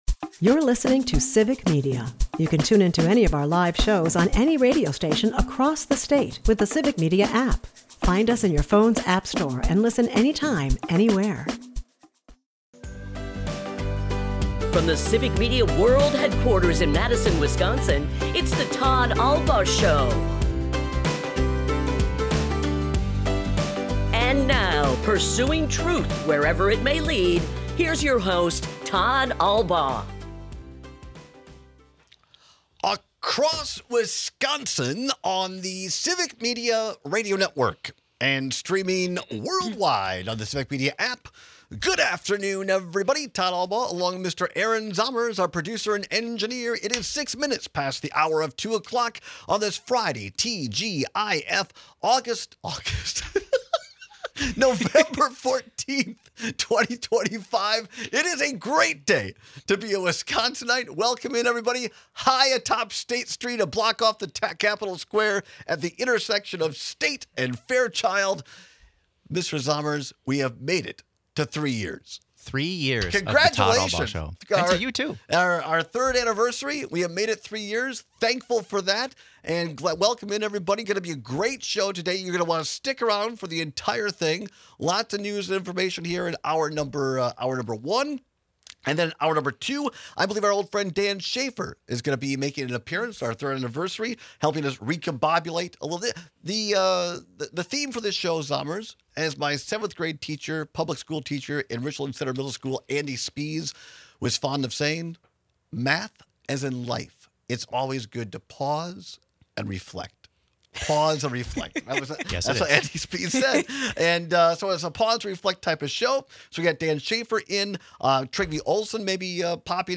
At the bottom of the hour, we take your calls and express our gratitude to you, the loyal listeners of this show.